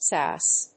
/sˈæs(米国英語)/